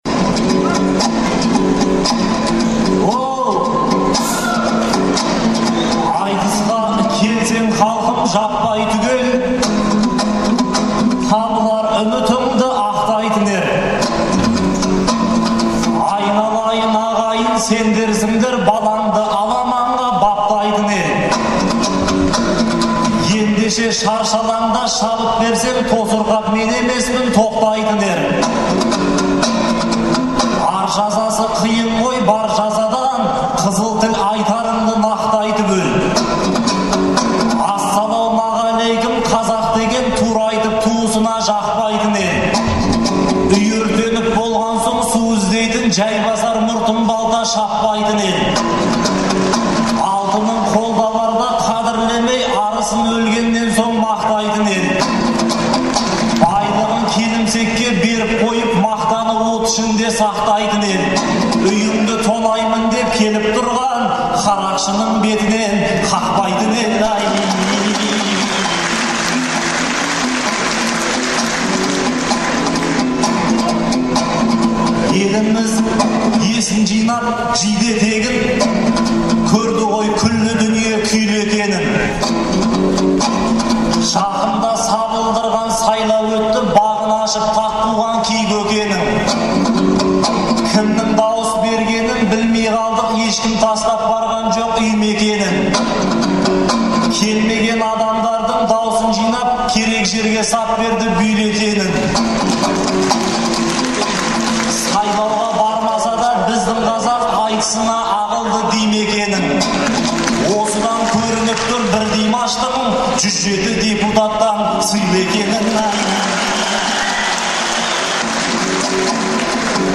Алматыдағы Балуан Шолақ атындағы спорт сарайында ақпанның 11-і мен 12-сі күні «Қонаевтай ер қайда» деген атпен айтыс өтті.